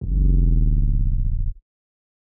end bass (slide).wav